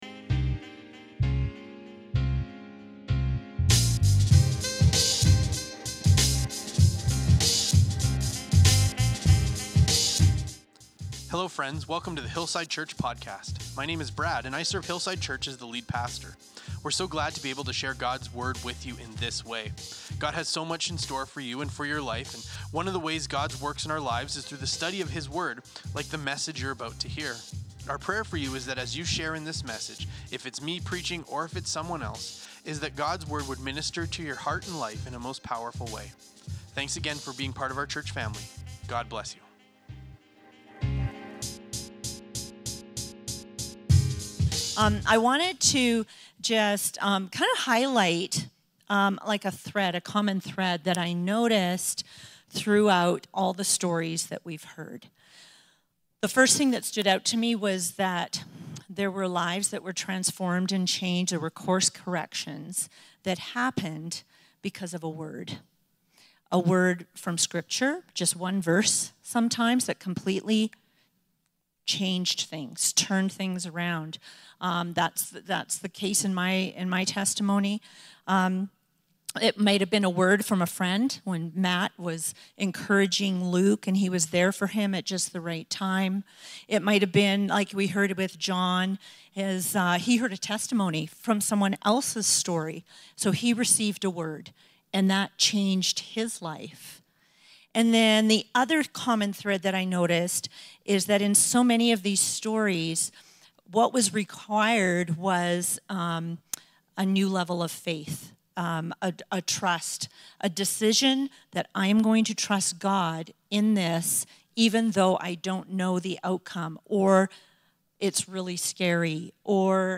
This week, we continue our inspiring series, 'This Is My Story,' by hearing the final set of testimonies from our Hillside Church family.